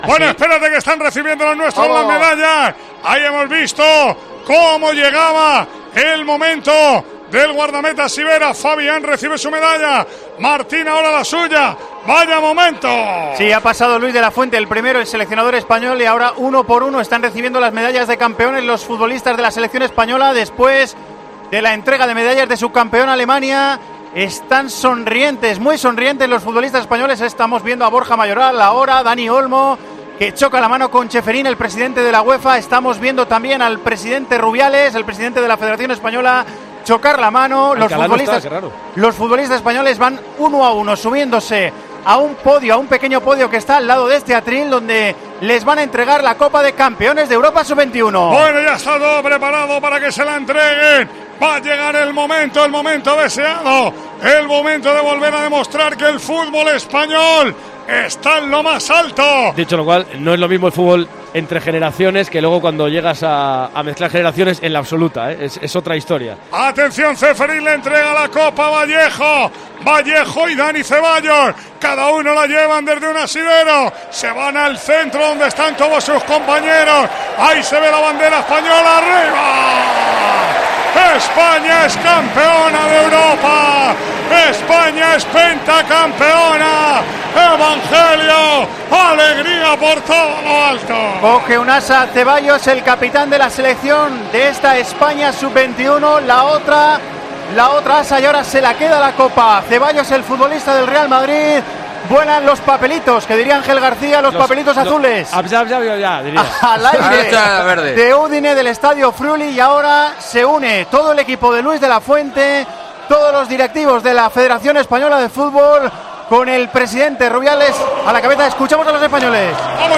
España ganó (2-1) a Alemania y se proclamó campeona de Europa sub-21. En Tiempo de Juego vivimos la entrega del trofeo.
Con Paco González, Manolo Lama y Juanma Castaño